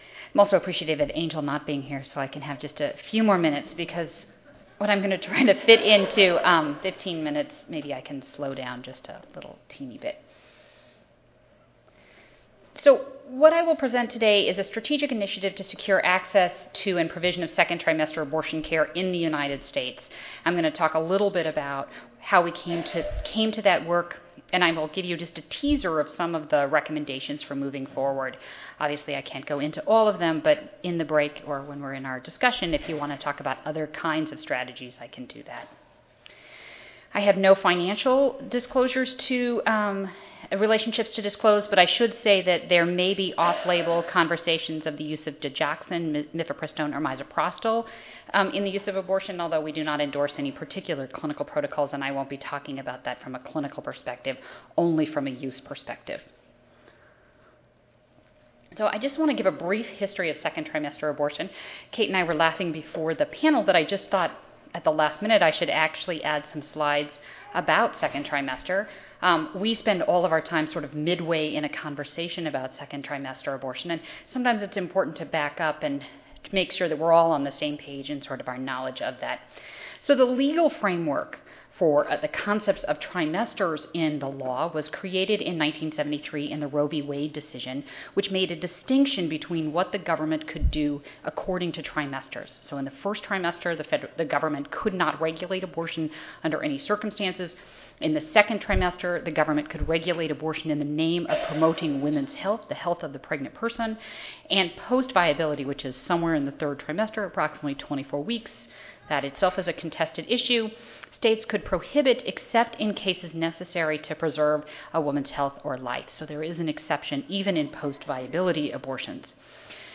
4374.0 Securing Access to Second-Trimester Abortion Tuesday, October 28, 2008: 4:30 PM Oral Over time many studies have found that 10% of abortions performed in the US occur in the second trimester. This session will explore how clinics and providers in diverse settings are working to secure access to safe 2nd trimester abortions. The panel will open with a comprehensive review of the scientific literature on second-trimester abortion, followed by a description of a strategic initiative to secure access to second trimester abortion through involvement of the public health community.